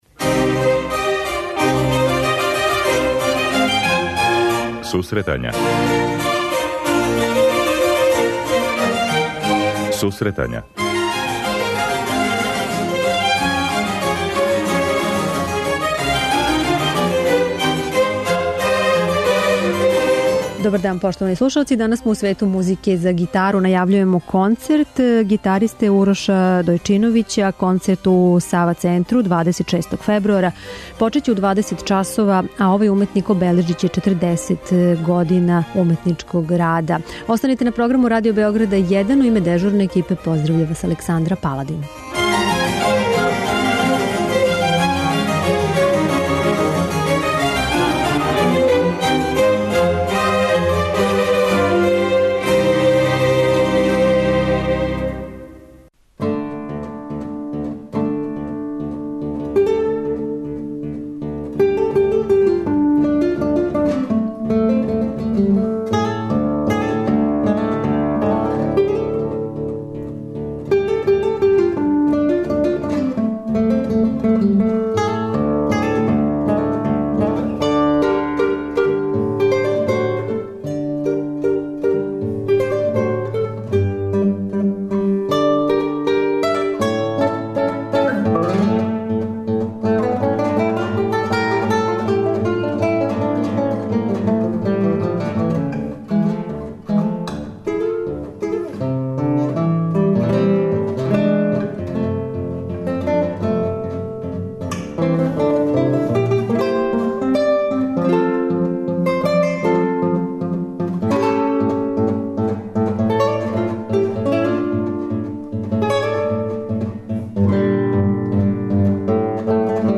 Са овим уметником разговарамо о концертима, педагошком и музиколошком раду.